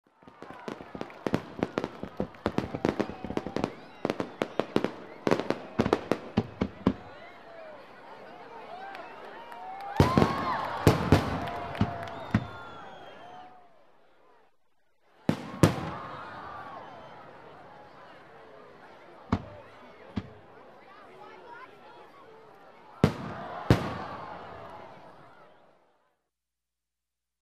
Звуки фейерверка, салюта
На этой странице собраны разнообразные звуки фейерверков и салютов: от одиночных хлопков до продолжительных залпов.